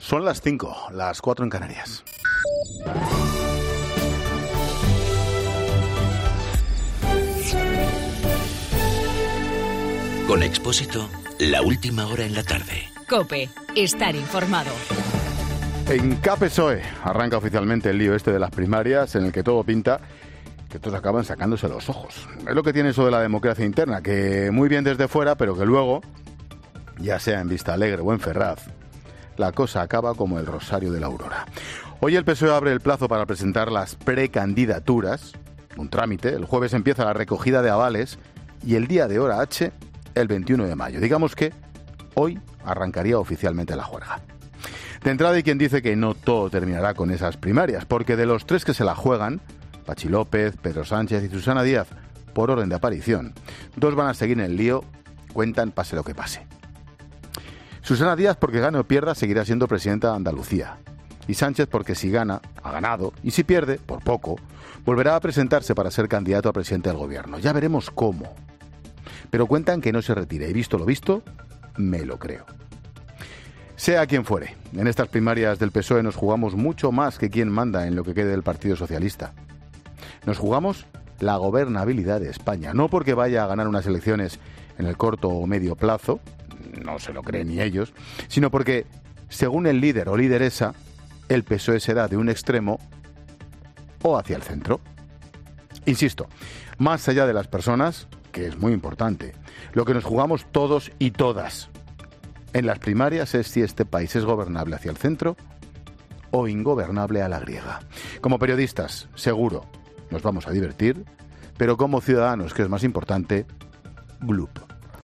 AUDIO: Monologo 17 h.